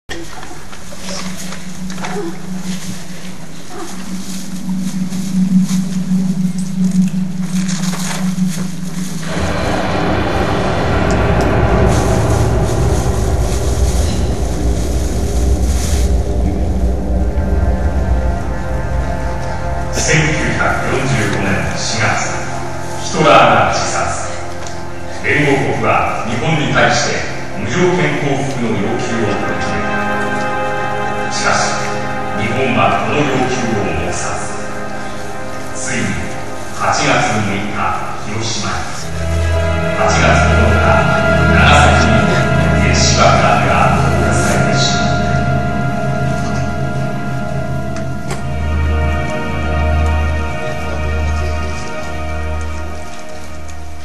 ジ ョ イ ン ト リ サ イ タ ル
平成十九年八月二十六日 (日)　於：尼崎アルカイックホール